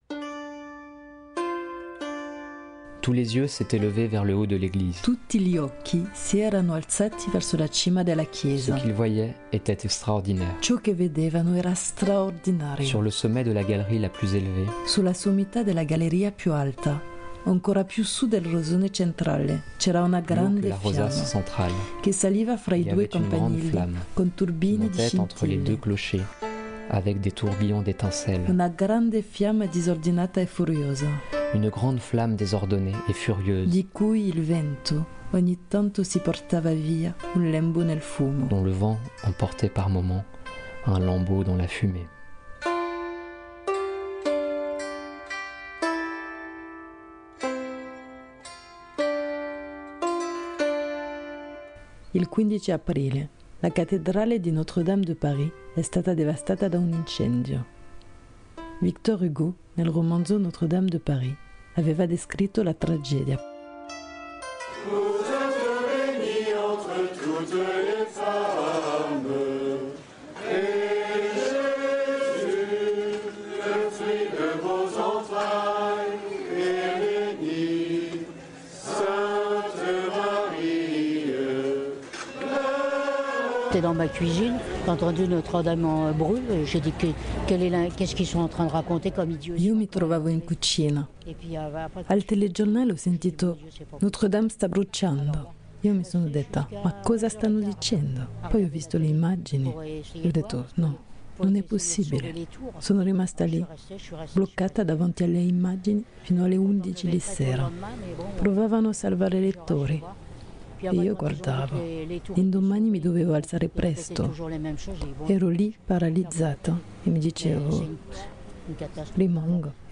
Turisti, curiosi, parigini, famiglie e telecamere di tutto il mondo dietro il perimetro di sicurezza, e ognuno con le sue considerazioni.
Un musicista seduto sul bordo della Senna canta il suo omaggio a Parigi guardando Notre-Dame.
Questo LASER vuole essere una testimonianza del presente sulla storia di Parigi attraverso voci, suoni, racconti e canti che ricordano ciò che fu, che è, e forse ciò che sarà Notre-Dame de Paris.